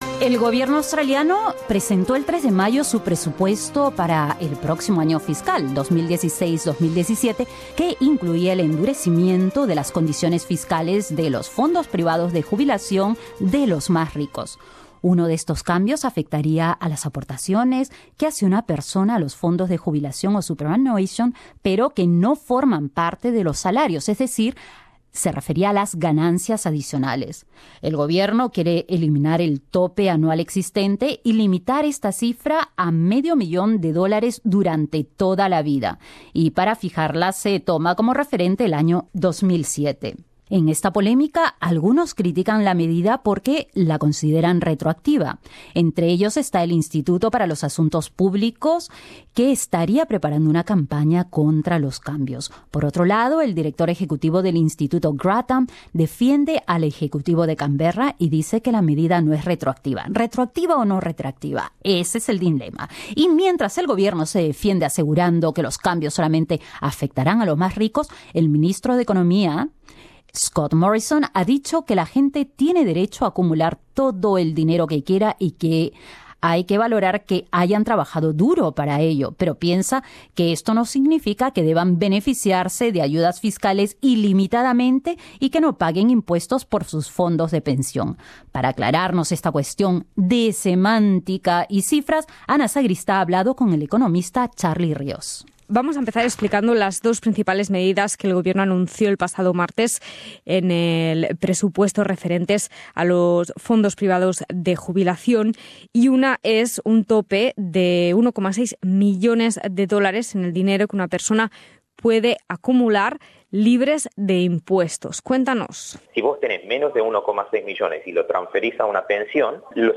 Escucha el análisis del economista